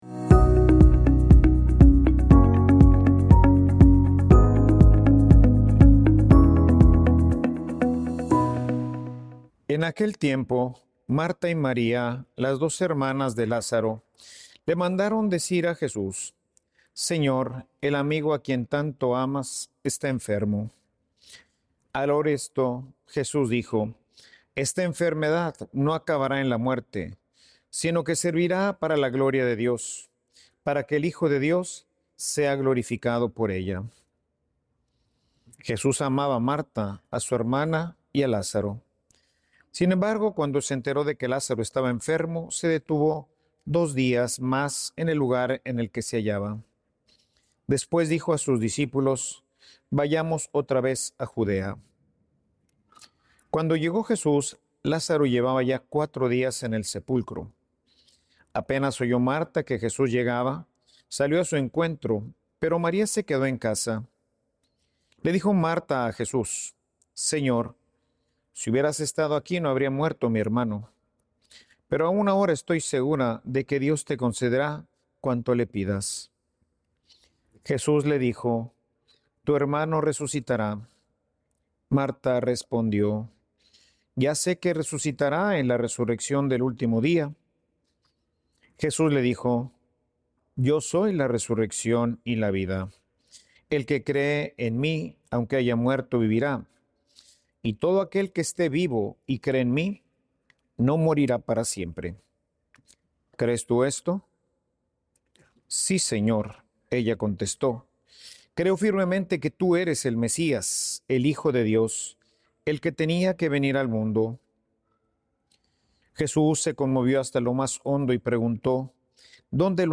Homilia_Somos_hombres_y_mujeres_con_cuerpos_de_carne.mp3